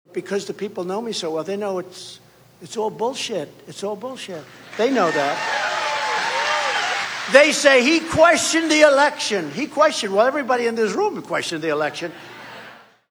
Earlier Wednesday afternoon, Trump told a crowd in Maquoketa that he’s been enduring what he called “made up allegations” since he first ran for president and that’s why his supporters are dismissing the criminal indictments he now faces.